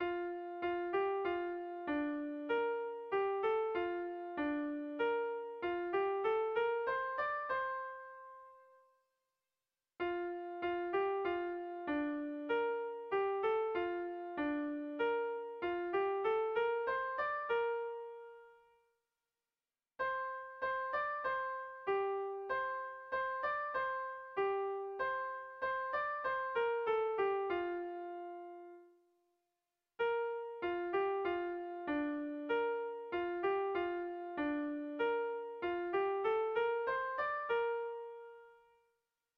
Zortziko handia (hg) / Lau puntuko handia (ip)
AABA